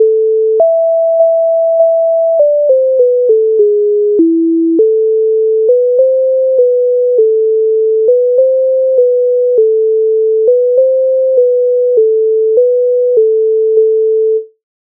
MIDI файл завантажено в тональності a-moll
Ой сивая та і зозуленька Українська народна пісня